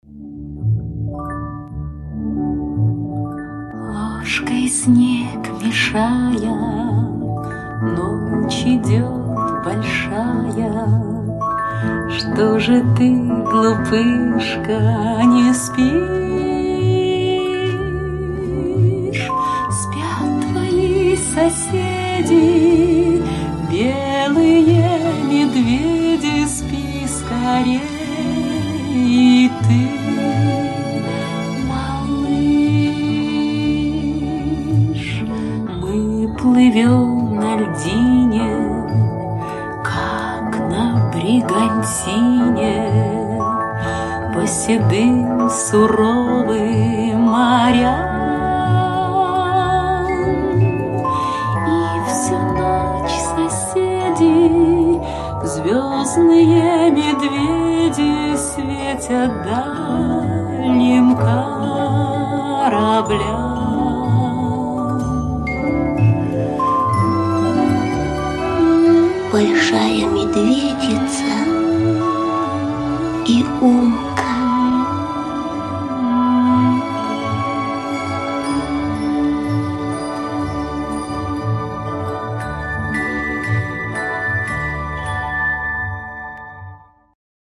Колыбельные